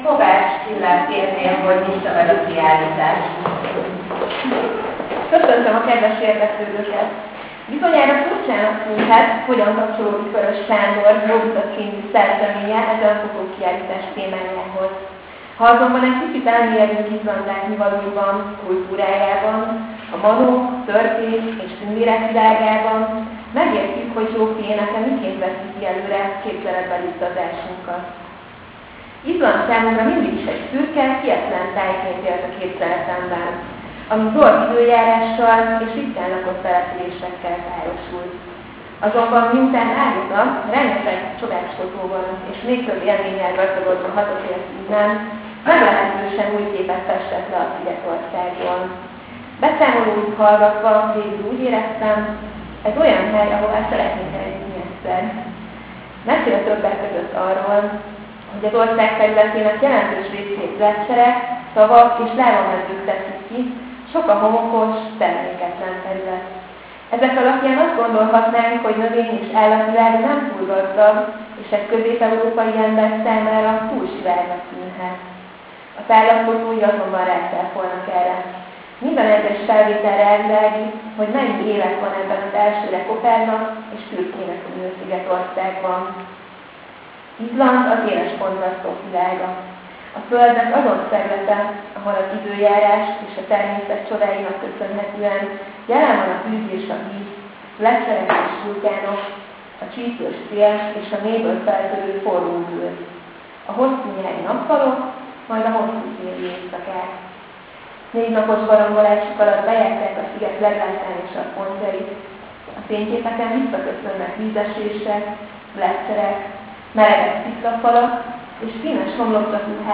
Fotokiállítás a galériában
megnyito.mp3